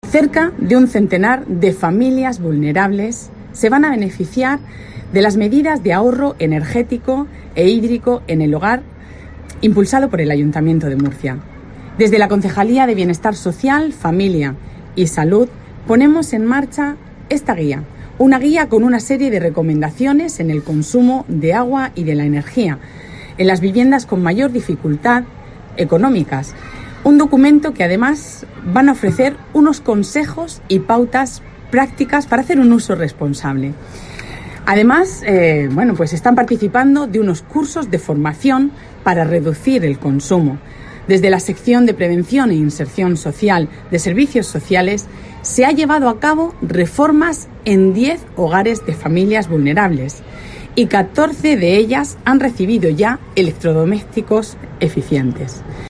Pilar Torres, concejala de Bienestar Social, Familia y Salud